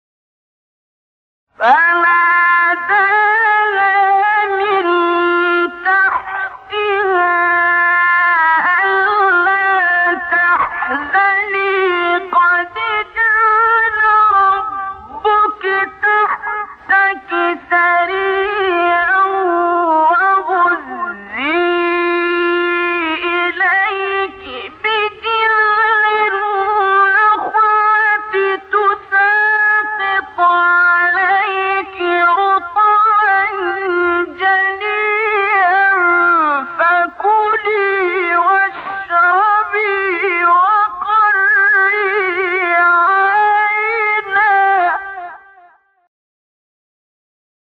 chahargahabdolbaset1.mp3